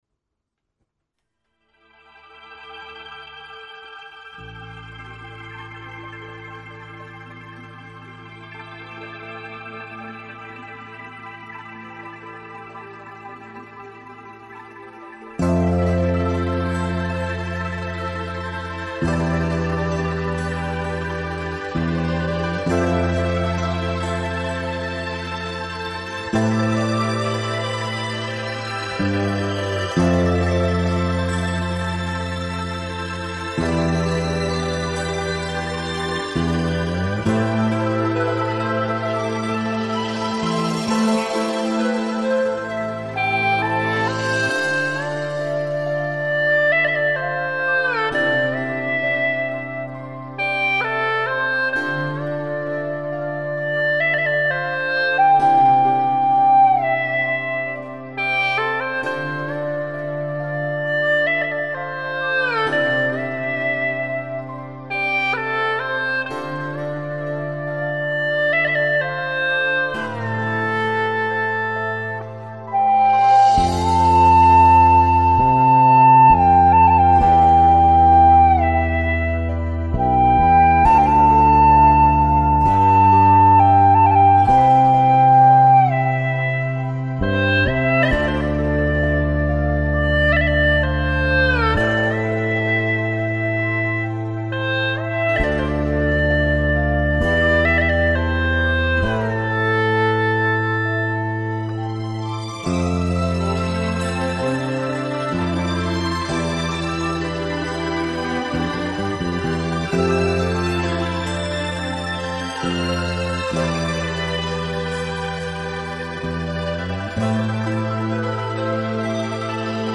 调式 : C